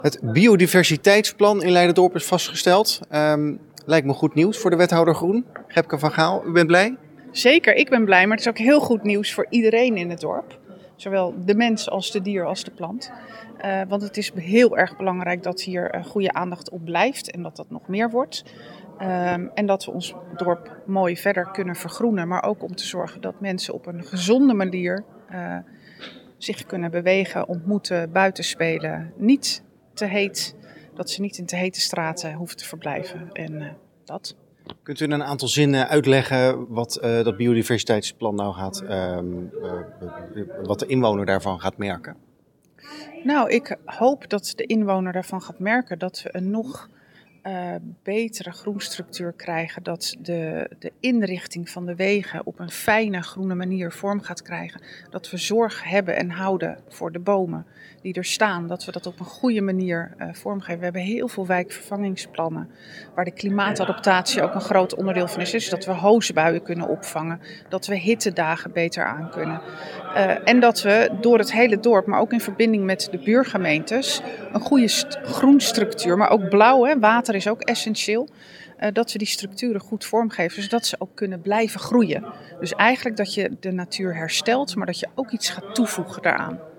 Wethouder Gebke van Gaal over het aangenomen Biodiversiteitsplan.
Gebke-van-Gaal-over-biodiversiteitsplan.mp3